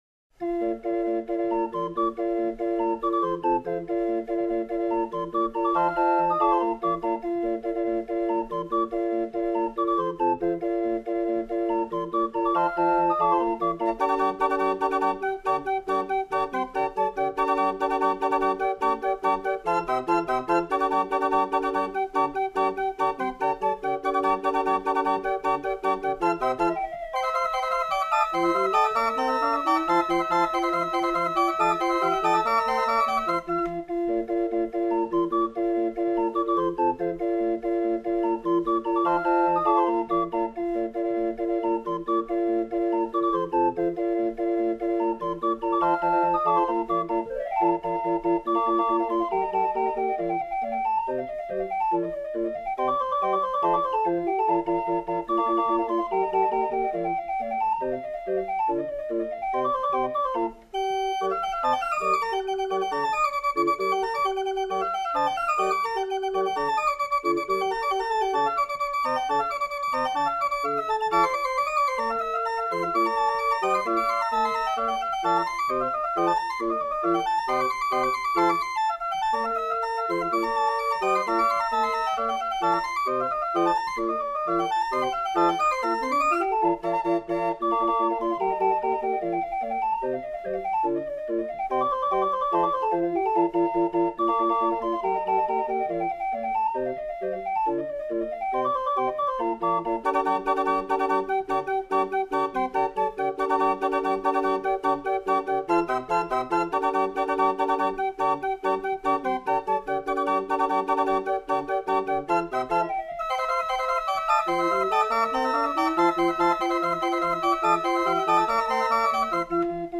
Wilhelm Tell Overture, Finale (Gioacchino Rossini, 1928; Melvin Wright, arr.) (instrumental)